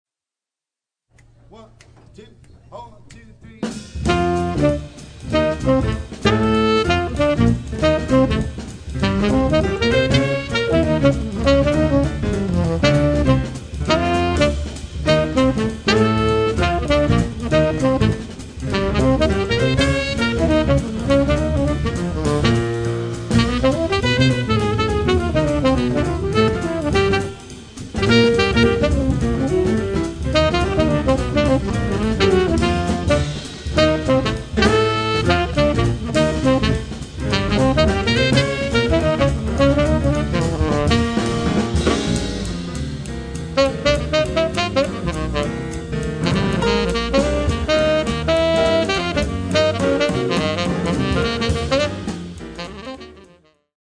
saxophone swings like a trapeze artist.
Piano
Bass
Sax
Drums
"straight ahead" jazz recording to date.
live in front of an audience in a club.
glasses clicking and the excitement in the room."